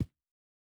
Shoe Step Stone Hard A.wav